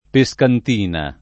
[ pe S kant & na ]